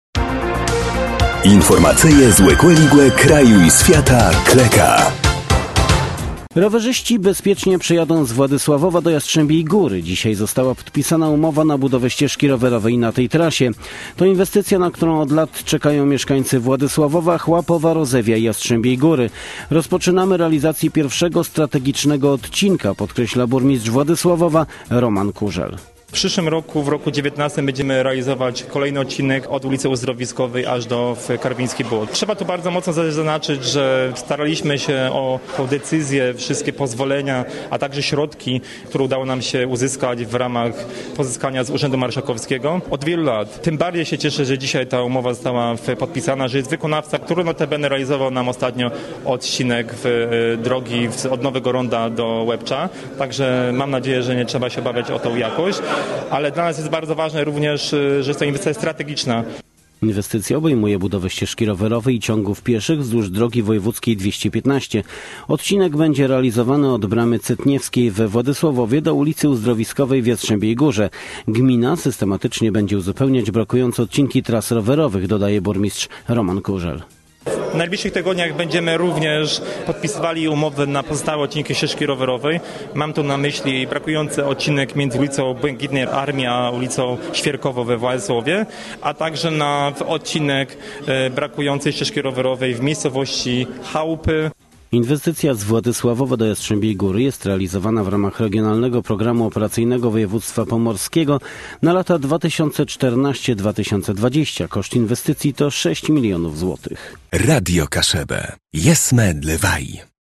– Rozpoczynamy realizację pierwszego, strategicznego odcinka – podkreśla burmistrz Władysławowa Roman Kużel.